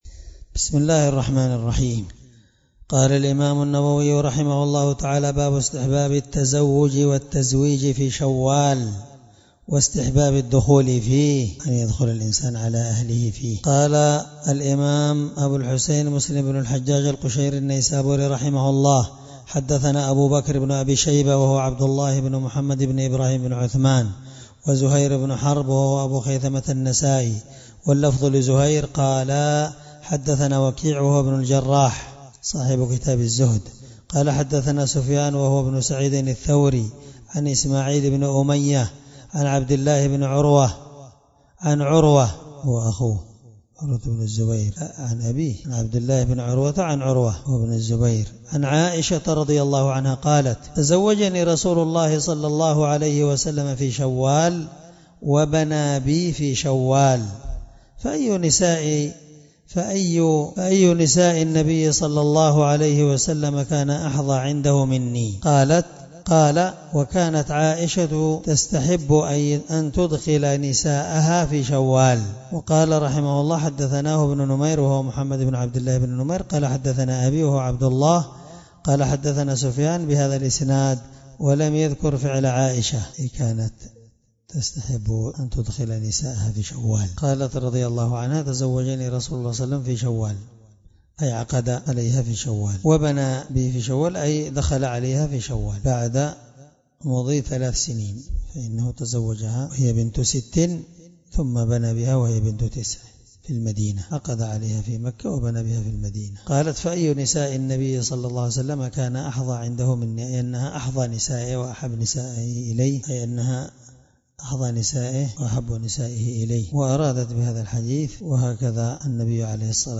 الدرس18من شرح كتاب النكاح حديث رقم(1423) من صحيح مسلم